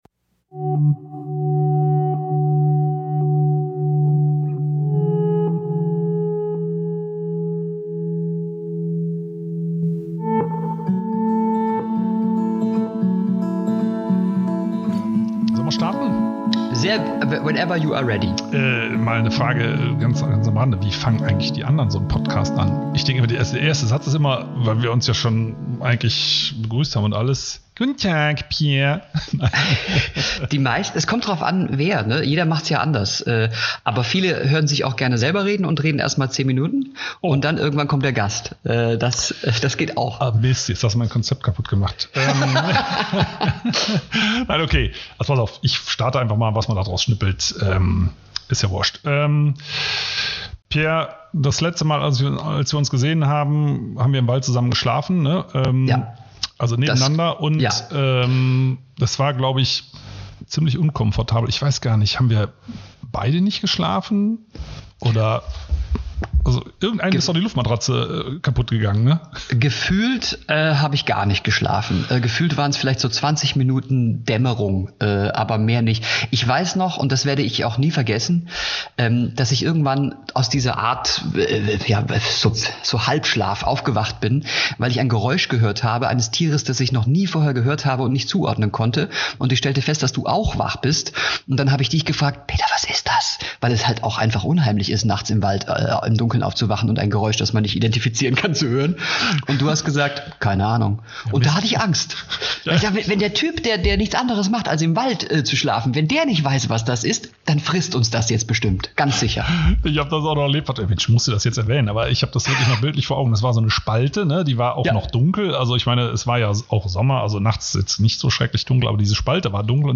Willkommen zur ersten Folge "Peter und der Wald", dem Podcast von Peter Wohlleben. Heute ist Pierre M. Krause zu Gast und wir sprechen über unsere Gemeinsame Nacht im Wald, Eichhörnchen, Julia Klöckner und den Wald.